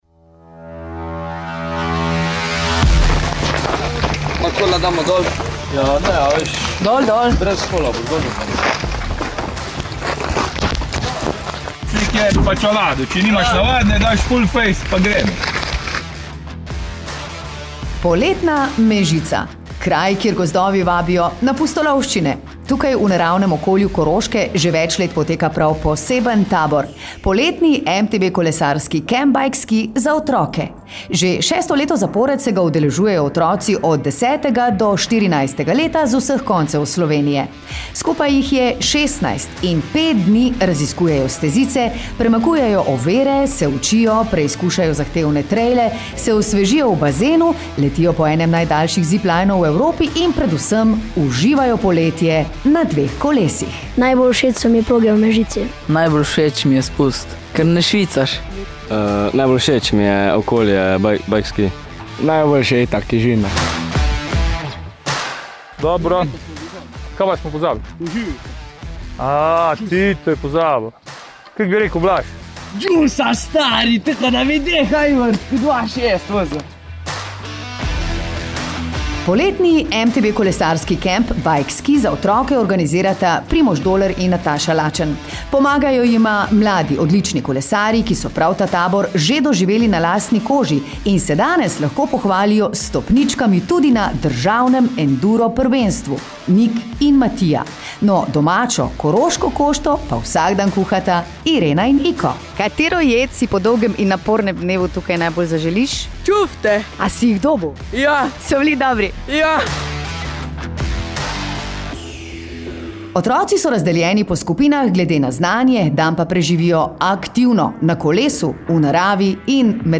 Reportaža iz MTB kolesarskega kampa BikeSki v Mežici